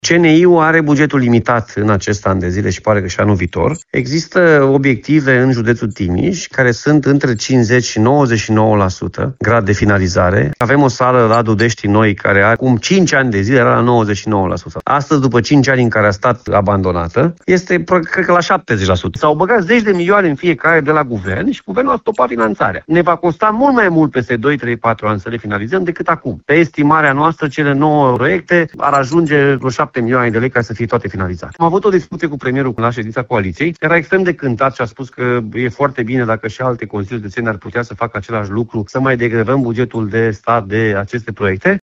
Președintele CJ Timiș, Alfred Simonis, anunță că a transmis o solicitare Guvernului pentru aprobarea finalizării acestor investiții.